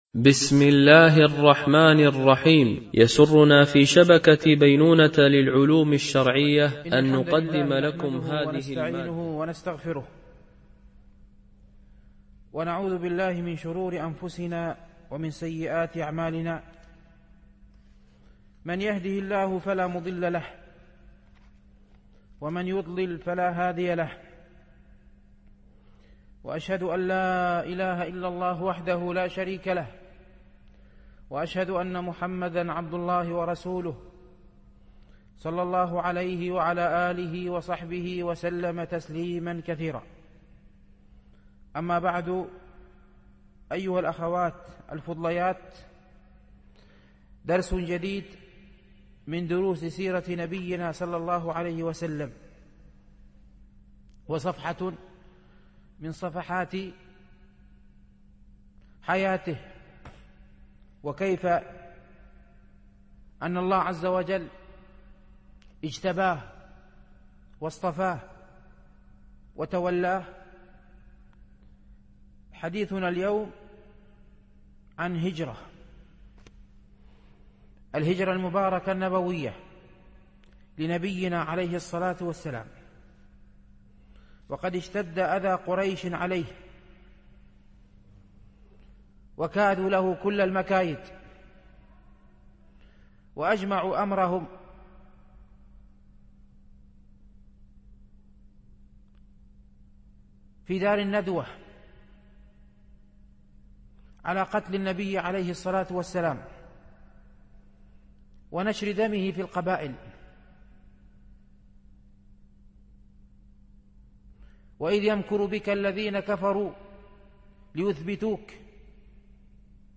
مهمات في السيرة ـ الدرس التاسع